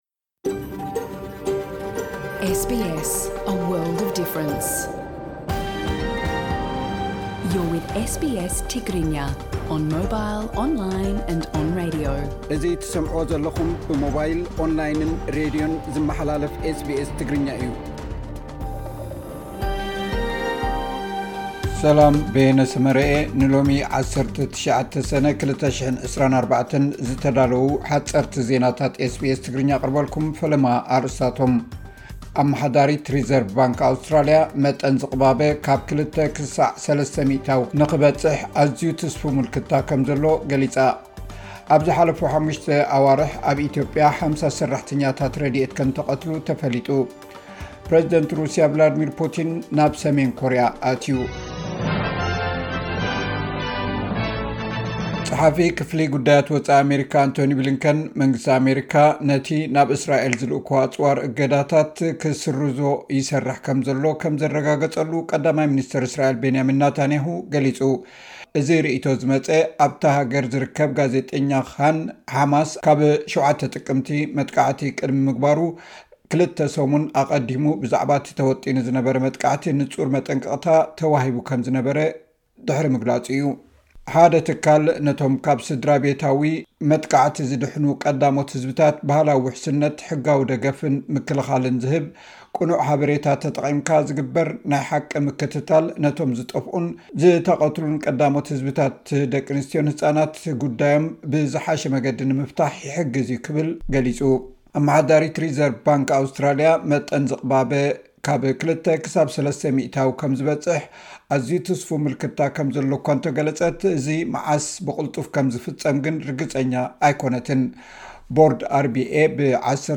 ሓጸርቲ ዜናታት ኤስ ቢ ኤስ ትግርኛ (19 ሰነ 2024)